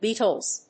/ˈbitʌlz(米国英語), ˈbi:tʌlz(英国英語)/